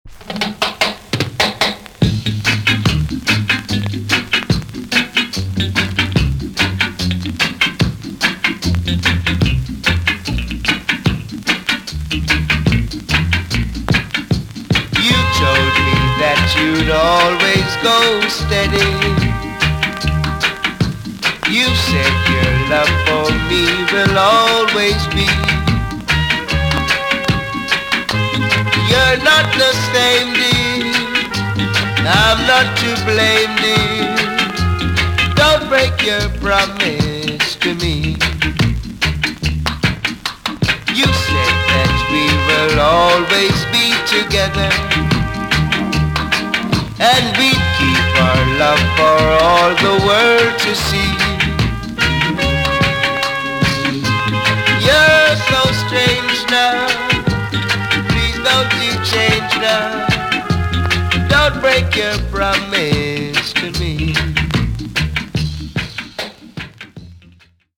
TOP >REGGAE & ROOTS
VG+ 少し軽いチリノイズがありますが良好です。
NICE VOCAL TUNE!!